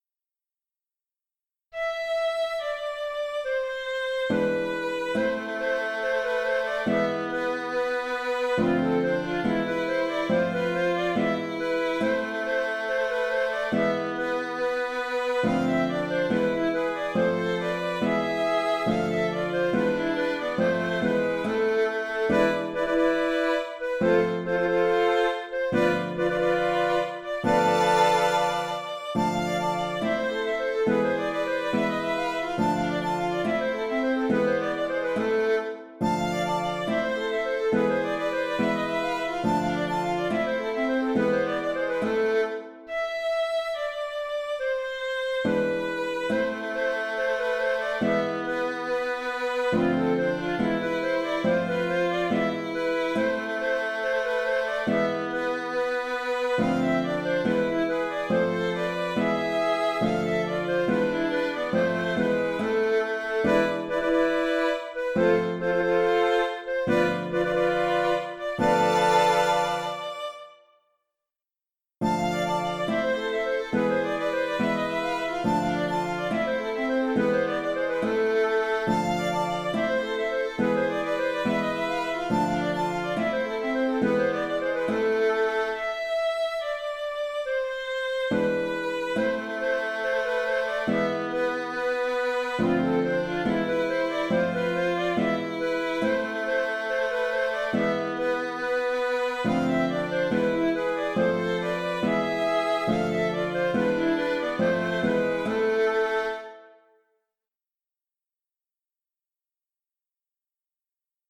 Galop Le Galop nantais est presque plus un jeu qu’une danse (à éviter sur parquet glissant). C’est une récréation dans un bal, et les danseurs en général s’amusent bien.
Trois parties : A) A jouer tranquillement.